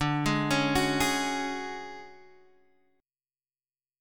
D Major 7th Flat 5th